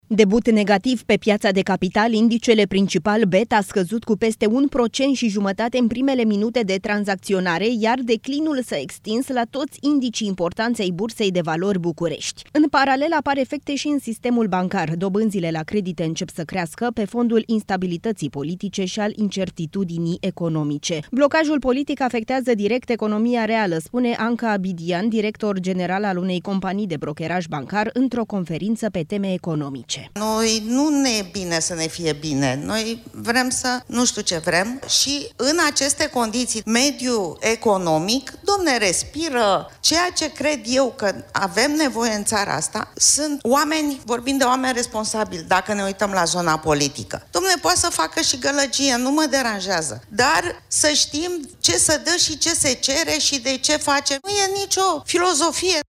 într-o conferință pe teme economice.